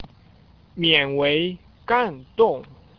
Start (High Speed Internet Only: mouse click the sentence number to hear its pronunciation in standard Chinese)